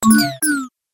Это могут быть сигналы смартфонов, ноутбуков, планшетов и других гаджетов.
Звук разряженной батареи